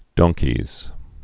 (dŏngkēz, dông-)